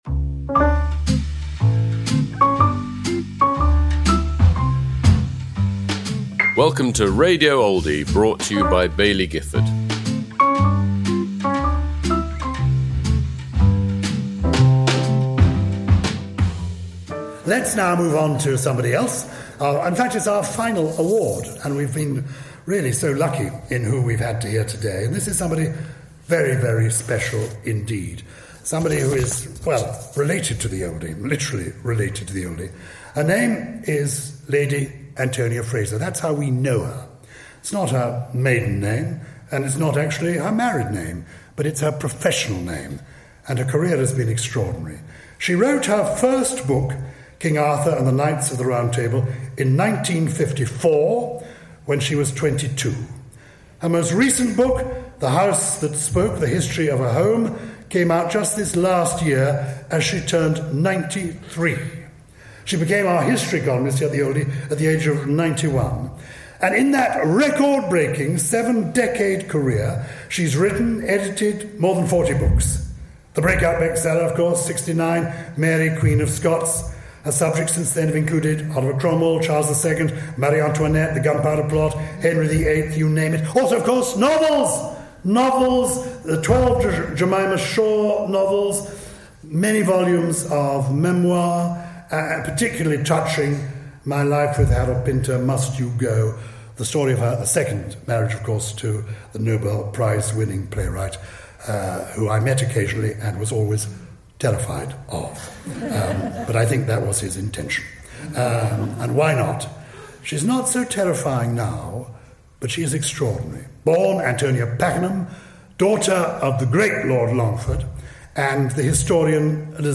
Lady Antonia Fraser at the 2025 Oldie of the Year Awards
Introduced by Gyles Brandreth.